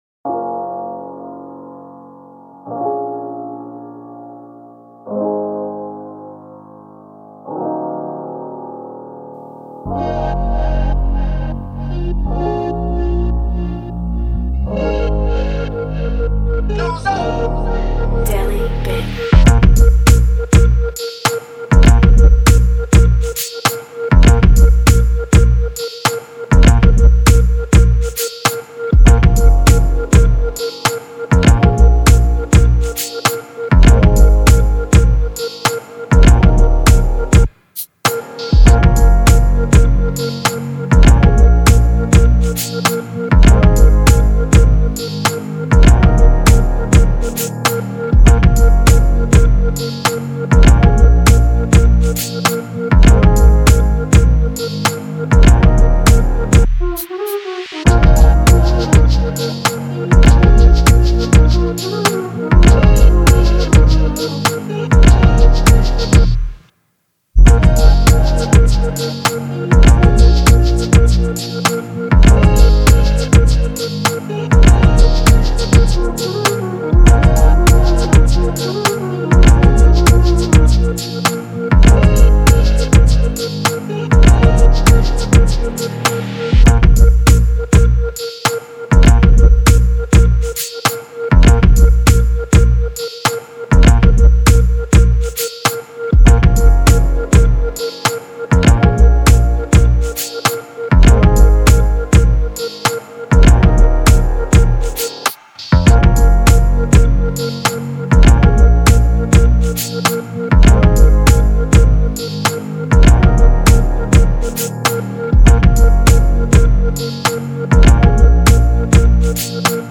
Hip-Hop Instrumentals